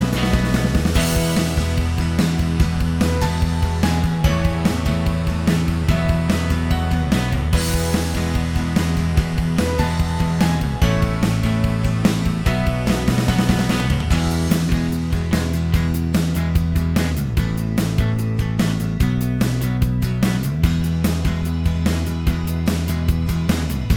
Minus All Guitars Rock 4:27 Buy £1.50